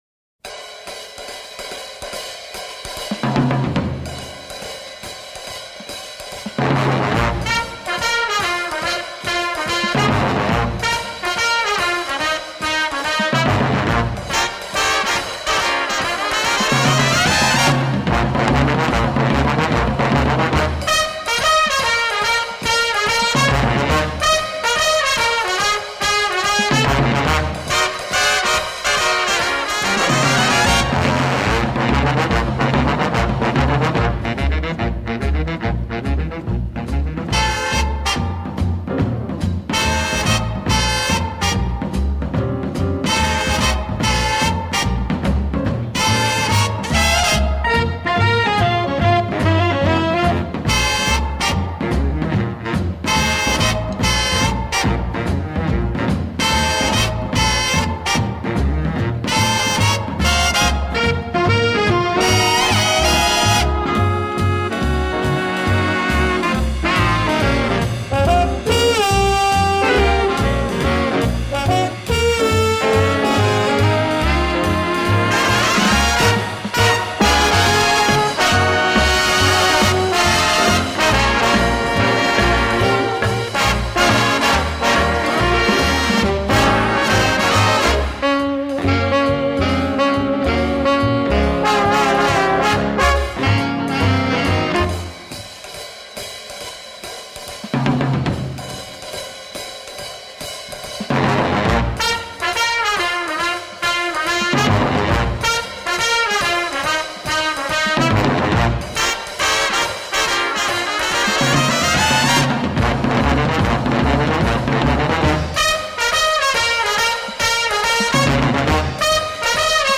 impactante banda sonora a ritmo de jazz